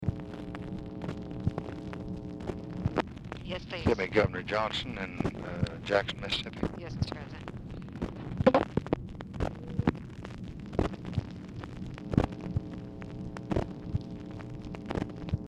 Telephone conversation # 4704, sound recording, LBJ and TELEPHONE OPERATOR, 8/4/1964, time unknown | Discover LBJ
Format Dictation belt